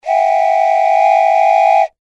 descargar sonido mp3 juguete 2
juguetes-juguete-1-.mp3